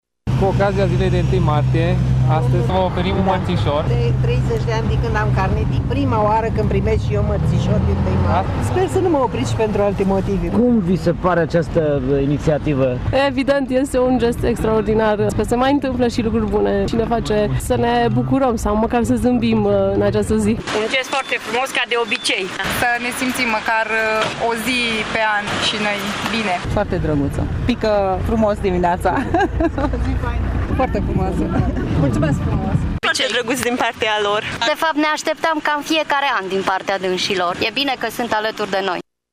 Unele șoferițe s-au speriat când le-a oprit poliția în trafic, altele știau deja și s-au bucurat când au primit în dar mărțișorul: